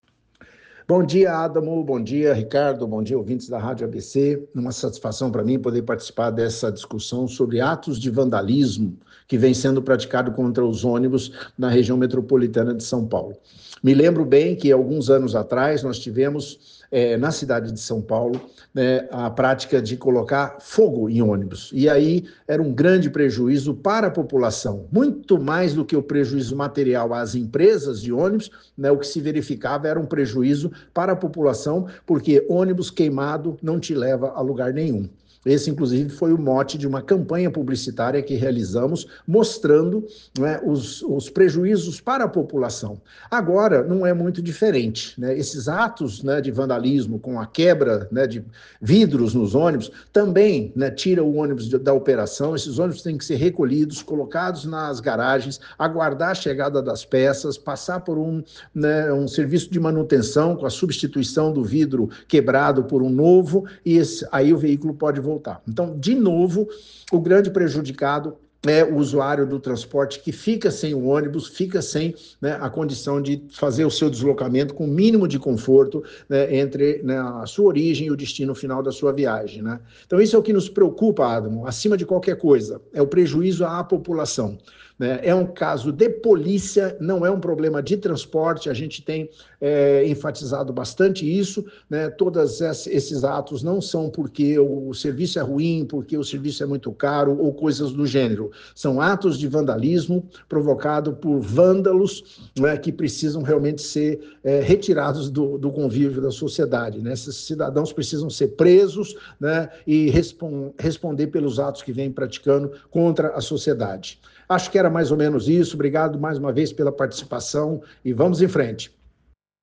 ENTREVISTA: Ataques a ônibus são, na verdade, ataques aos cidadãos.